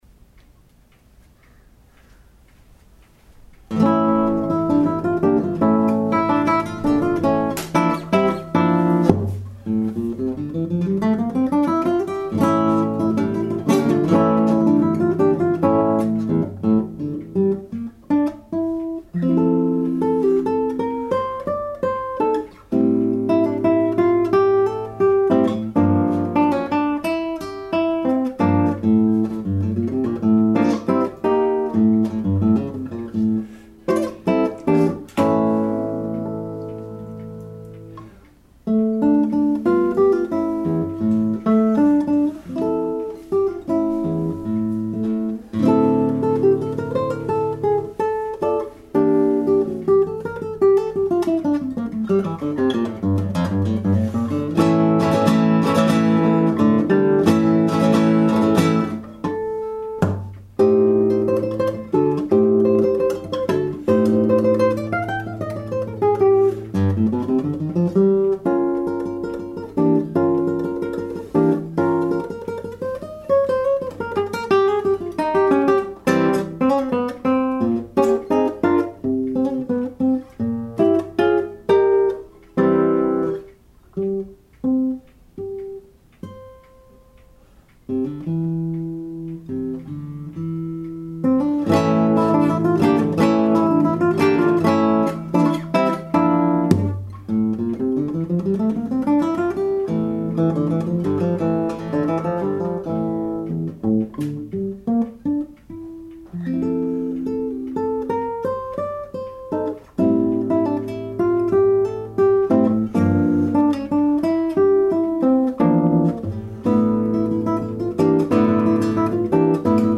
The top is Carpathian Spruce and back and sides are Cocobolo.